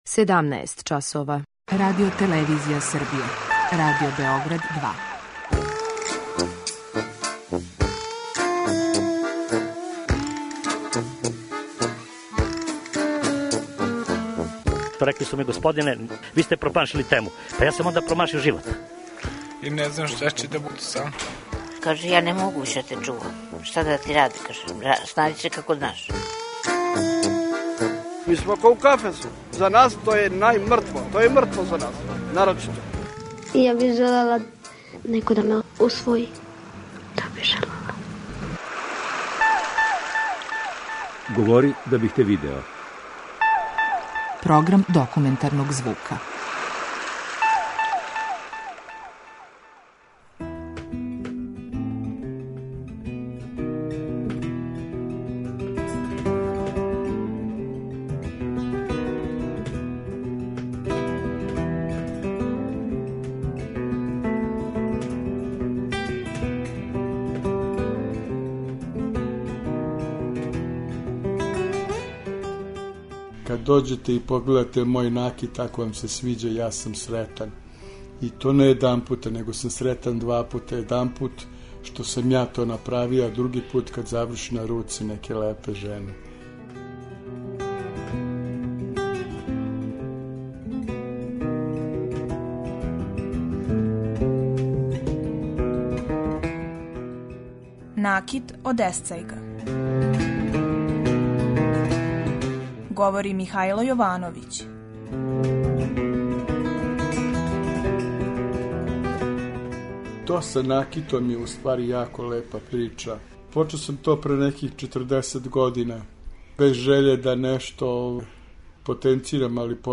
Документарни програм: Накит од есцајга